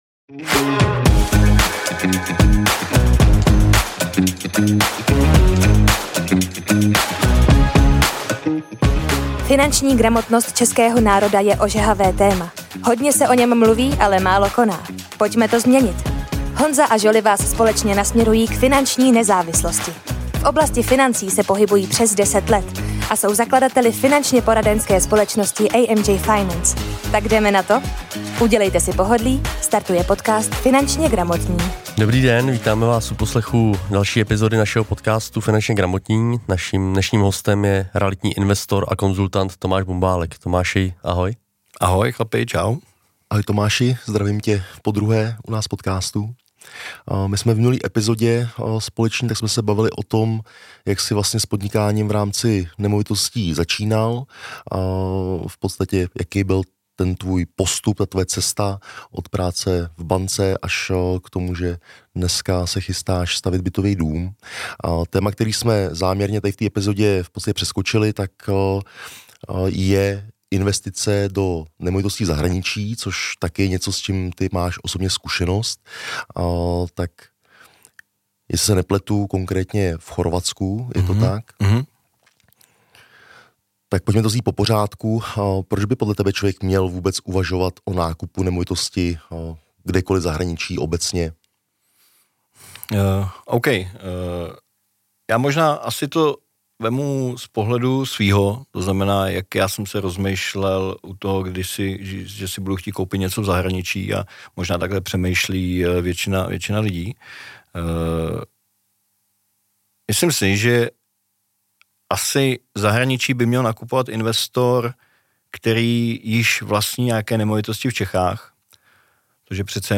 Ve druhé části našeho rozhovoru